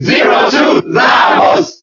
Category:Zero Suit Samus (SSBB) Category:Crowd cheers (SSBB) You cannot overwrite this file.
Zero_Suit_Samus_Cheer_German_SSBB.ogg